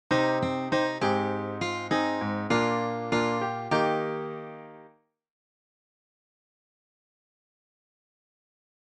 LISTEN] I chose to start on an Eb note, as it fits nicely with the Cm chord that my progression begins with. You can hear that my melodic fragment starts relatively low, then makes that leap upward, comes back down, and then ends on a slight upturn. It’s short, catchy, and memorable.